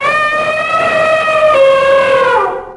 دانلود آهنگ فیل 5 از افکت صوتی انسان و موجودات زنده
دانلود صدای فیل 5 از ساعد نیوز با لینک مستقیم و کیفیت بالا
جلوه های صوتی